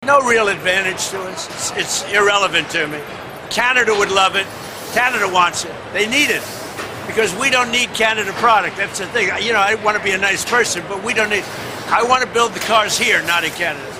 Speaking while touring a Ford manufacturing plant in Michigan, Trump said he wants to see more vehicles built in the United States and argued that American consumers do not need cars produced in Canada or Mexico.